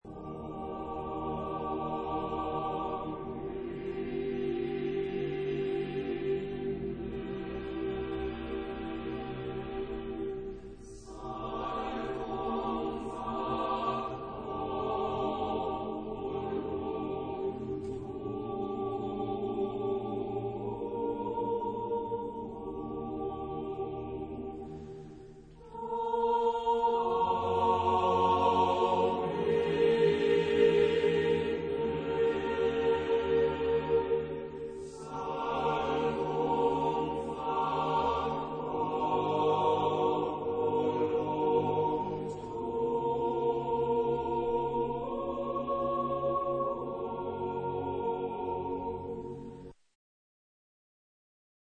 Género/Estilo/Forma: Sagrado ; Coro
Tipo de formación coral: SSAATTBBB  (9 voces Coro mixto )
Tonalidad : mi bemol mayor